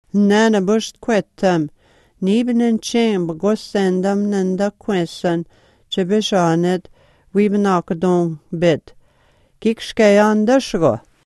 geese_19.mp3